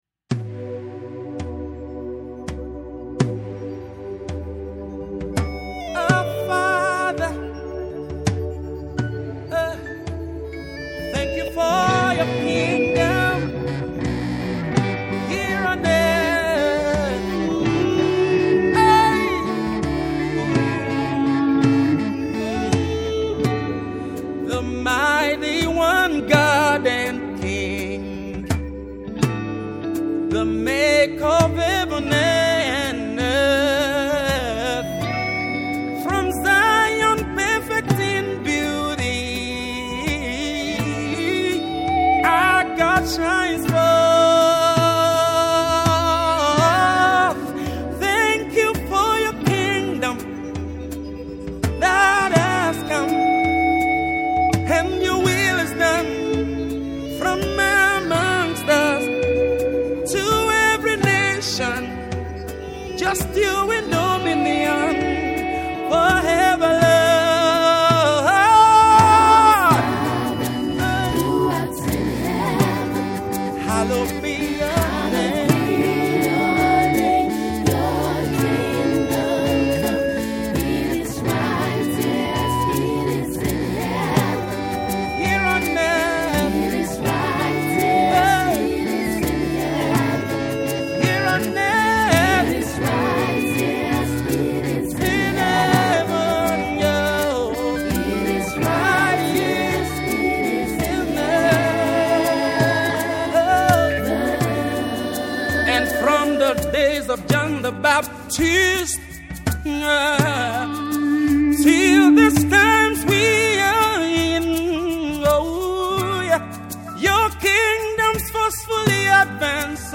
Gospel
a fusion of rock and soul